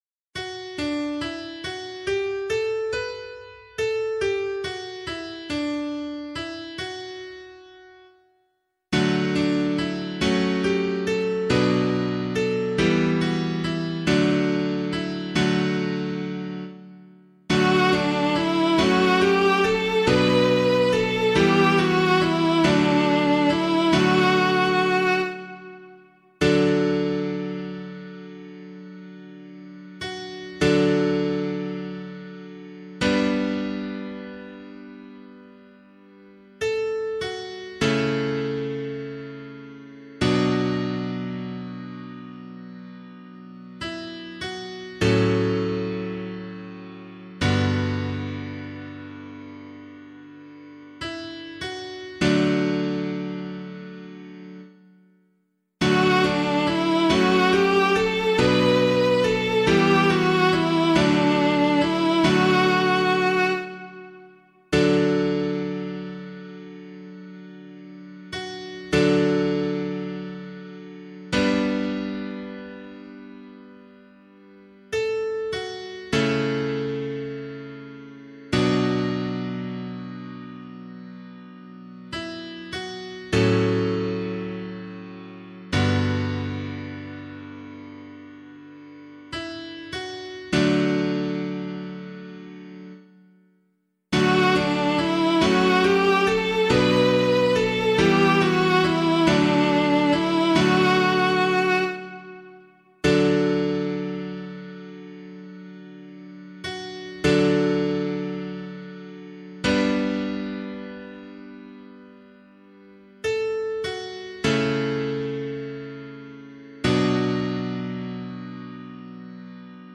031 Pentecost Vigil Psalm 1 [Abbey - LiturgyShare + Meinrad 5] - piano.mp3